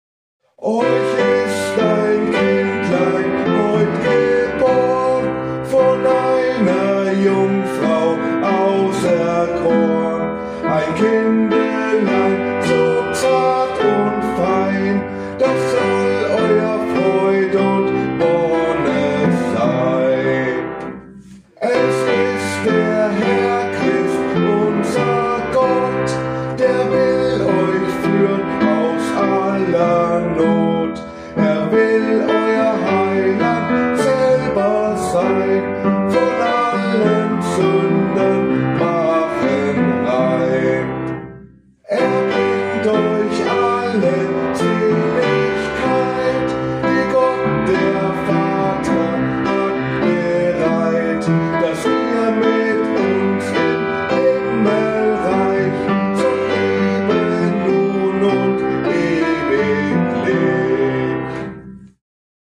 Gesang, Gitarre
Klavier